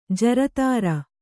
♪ jaratāra